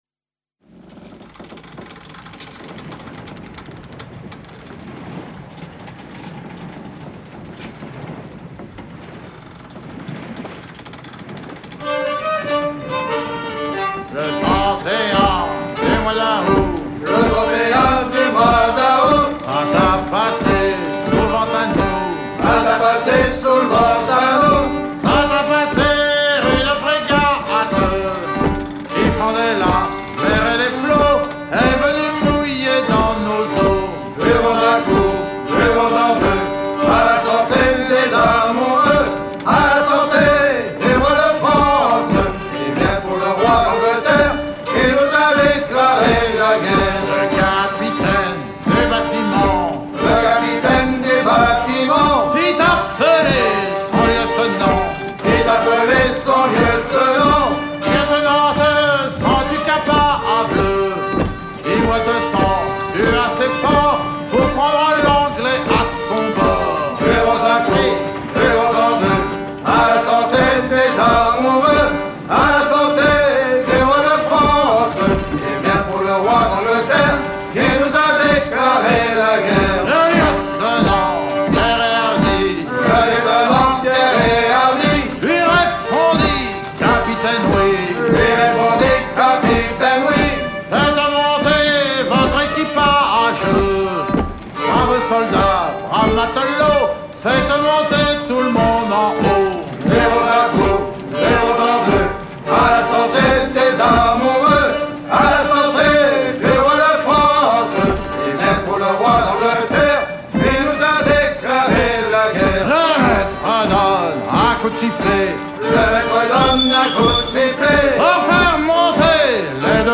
danse : contredanse
Pièce musicale éditée